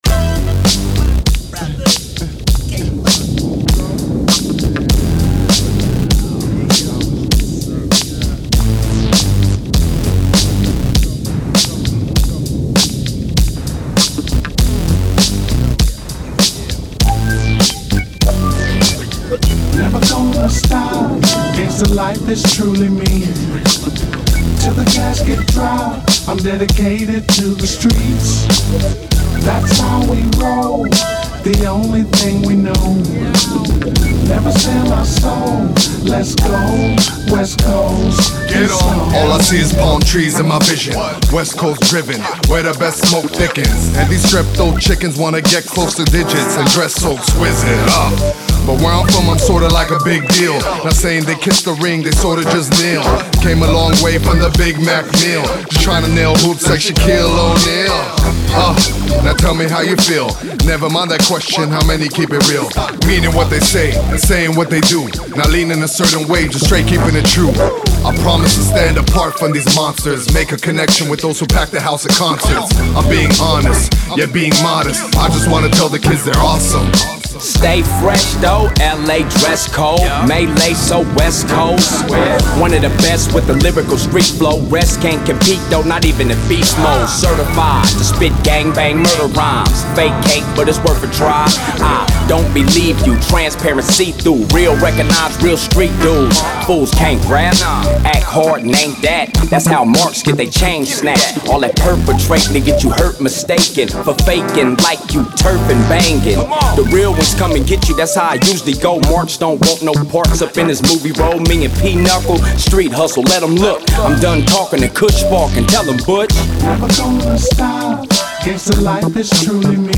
With a strong vocal tone and a demanding presence